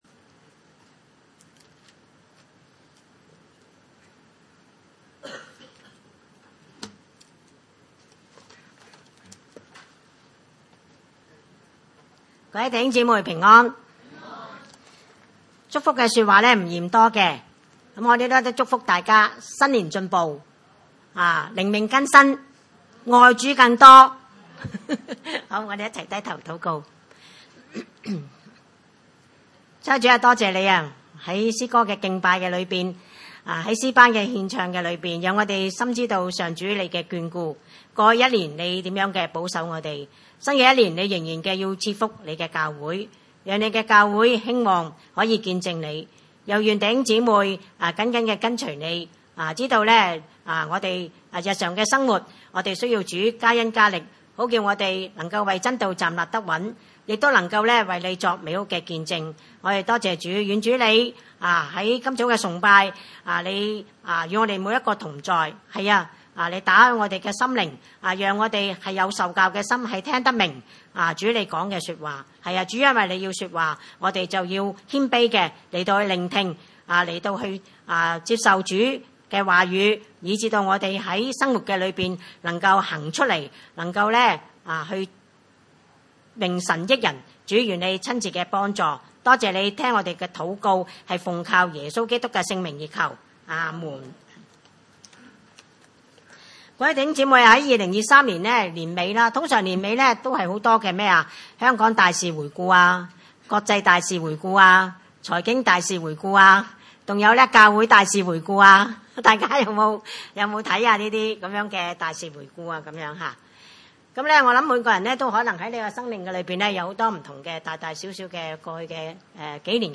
經文: 約翰福音二十一章18-22節 崇拜類別: 主日午堂崇拜 我實實在在的告訴你，你年少的時候，自己束上帶子，隨意往來；但年老的時候，你要伸出手來，別人要把你束上，帶你到不願意去的地方。